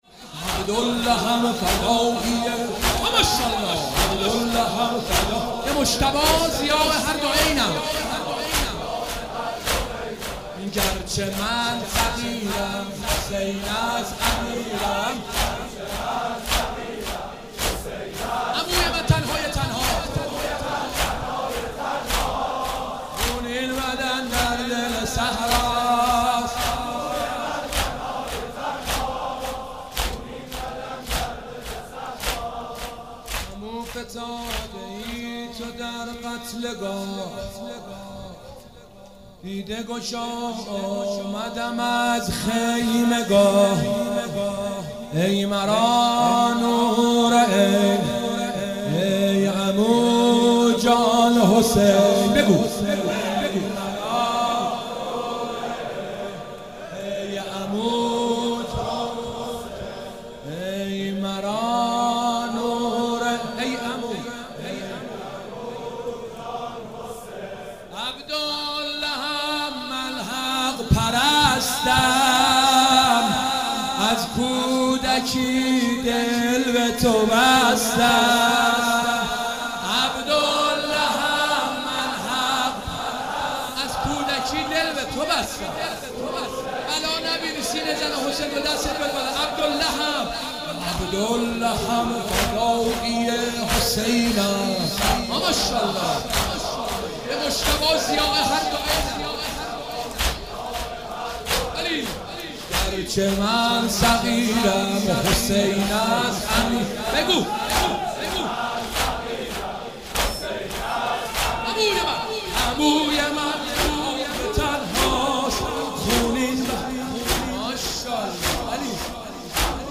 دو دمه